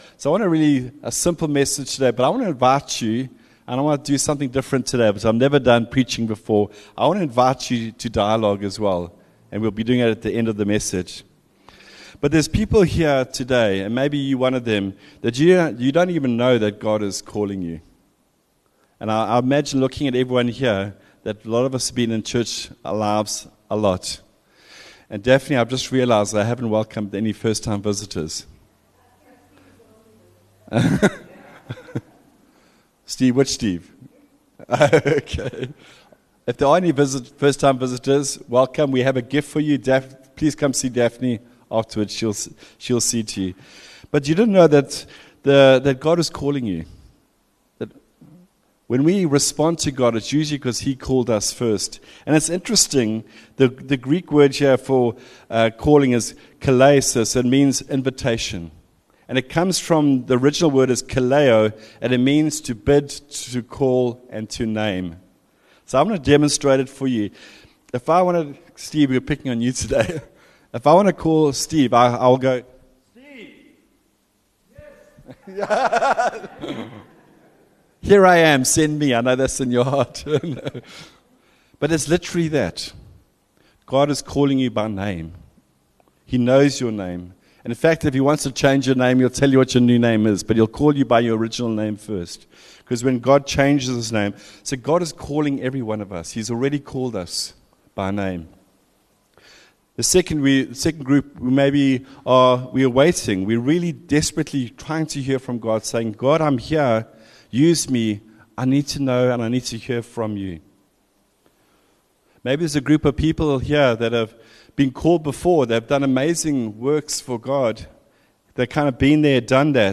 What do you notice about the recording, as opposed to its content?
Upper Highway Vineyard Sunday messages